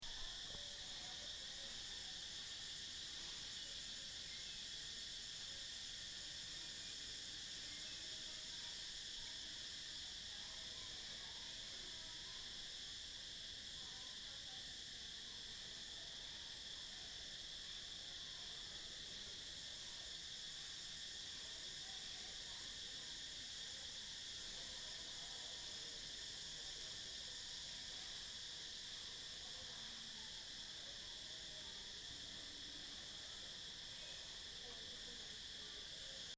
On the Ta Prohm temple, Angkor.